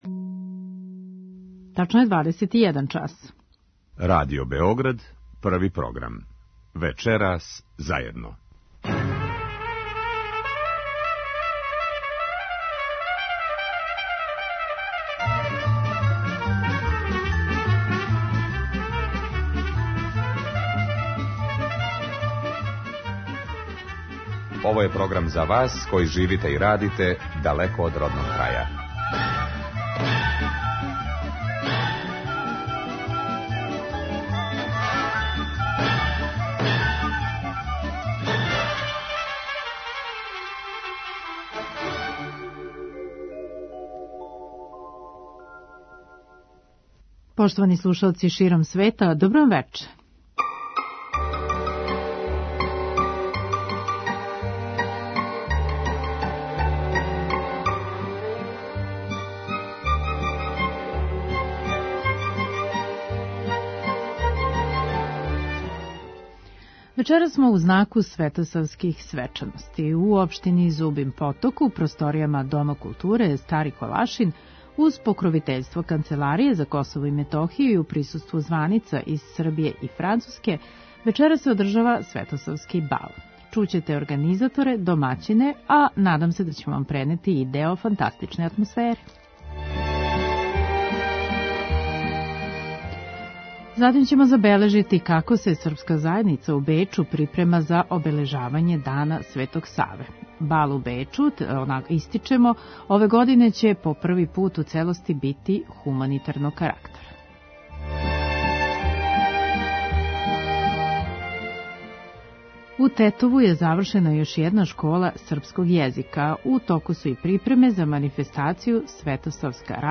У општини Зубин поток, у просторијама Дома културе “Стари Колашин“ , уз покровитељство Канцеларије за КиМ и у присуству званица из Србије и Француске, вечерас се одржава светосавски бал. Чућете организаторе, домаћине, и неке од учесника овог бала.